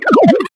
surge_dryfire_01.ogg